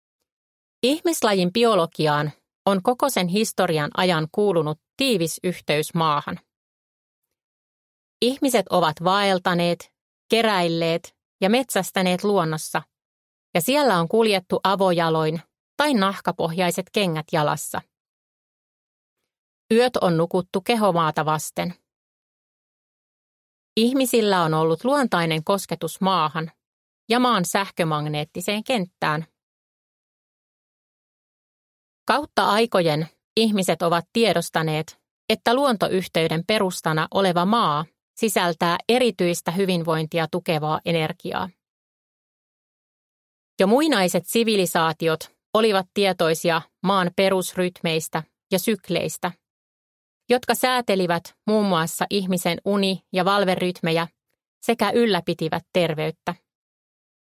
Metsän hoitava syli – Ljudbok